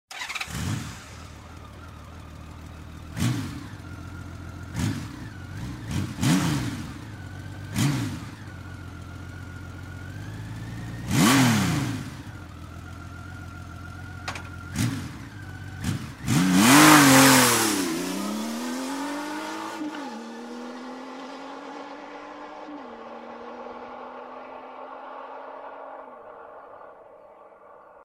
Motorn låter dock mer lik en Porsche än det karakteristiska twin-dunket som annars är praxis på denna typ av hoj.